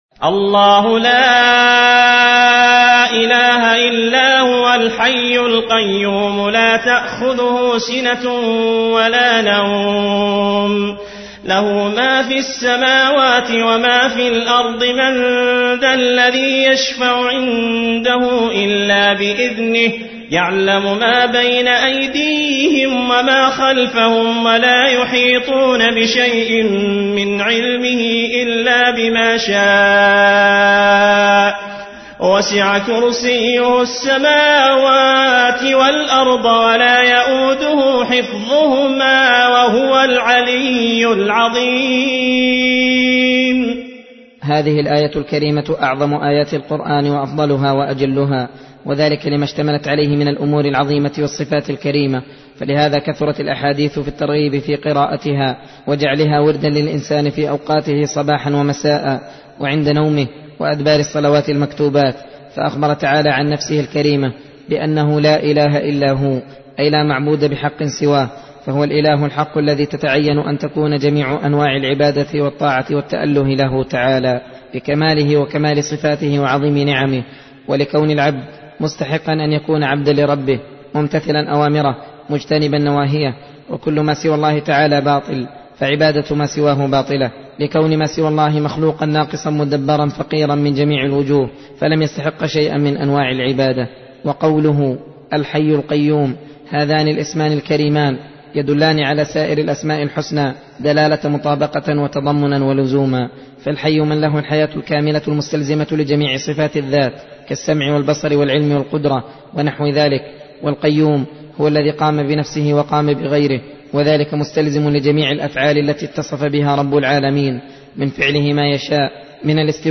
درس (22) : تفسير سورة البقرة: (255-262)